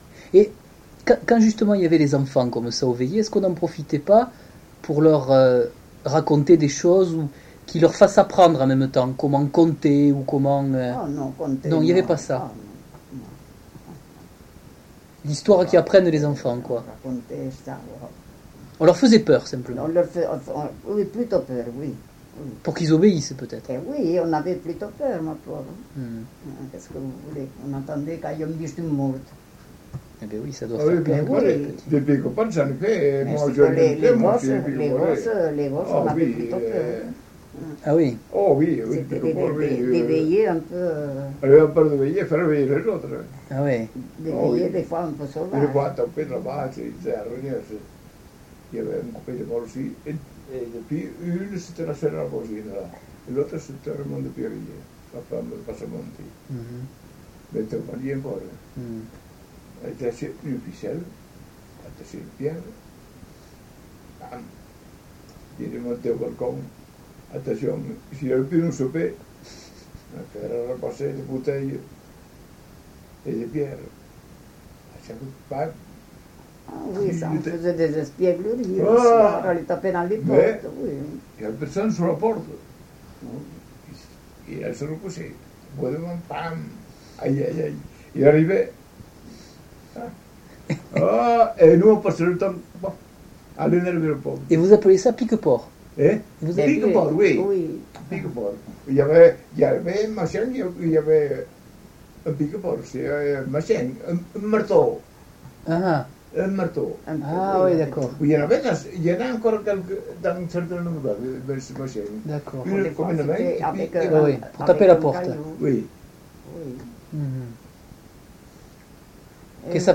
Aire culturelle : Couserans
Lieu : Ayet (lieu-dit)
Genre : témoignage thématique